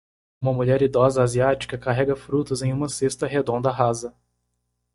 Pronounced as (IPA) /ˈʁa.zɐ/